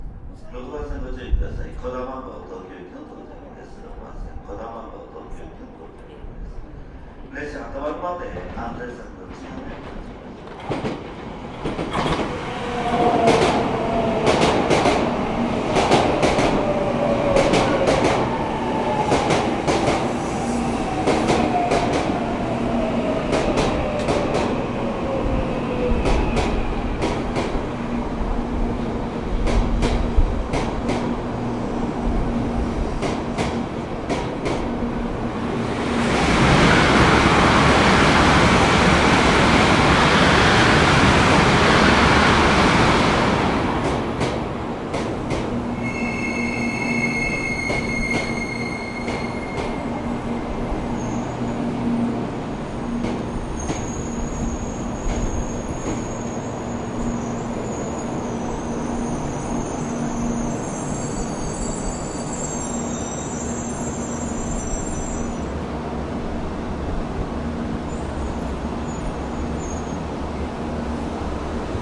快车 "从远处看
描述：对一些日本人来说是一种'熟悉'的声音。新干线是日本最快的地面公共交通工具，经常在居民区中间行驶。
我在离铁路120米左右的地方录制了这个声音。
XY立体声。
标签： 遥远 现场记录 传球 新干线 火车
声道立体声